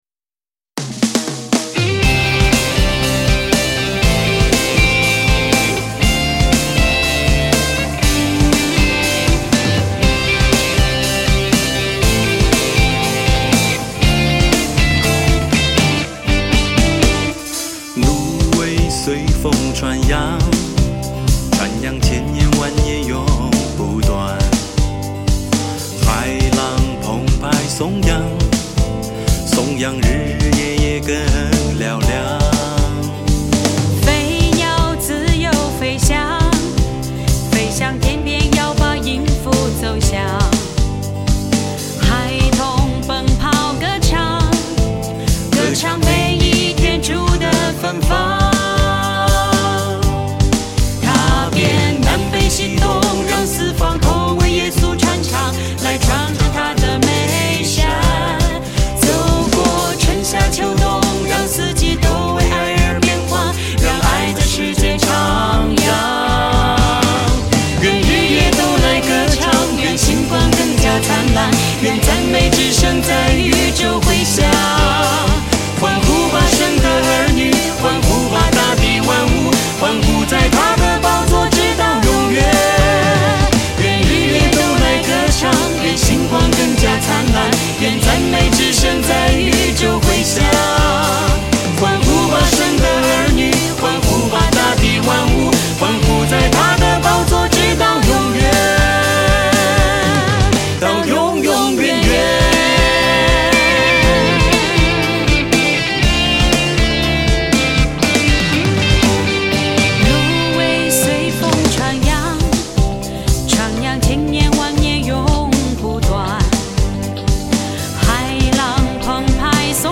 HAKA祷告敬拜MP3 启示性祷告： 持续祷告： 祈求神的旨意成就在我们身上，启示性恩膏临到，明白身份，听到呼召，进入命定！